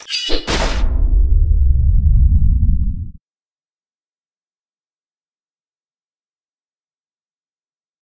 swingsword.ogg.mp3